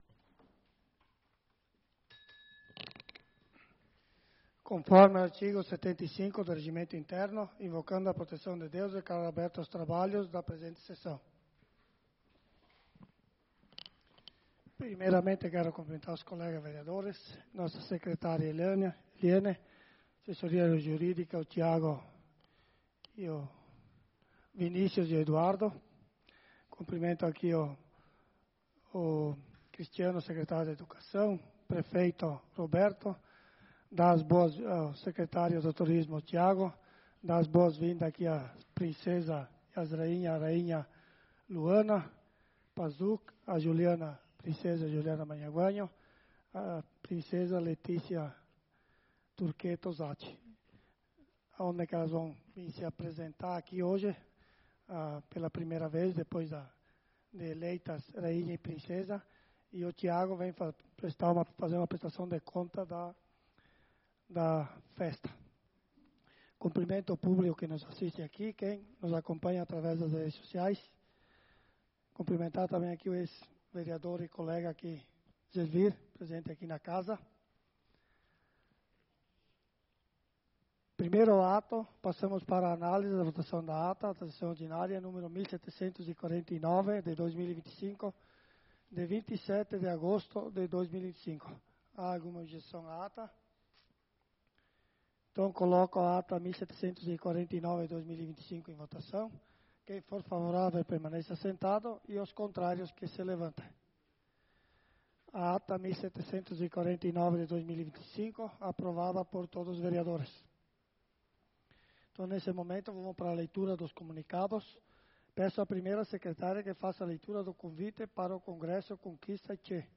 Sessão Ordinária do dia 03/09/2025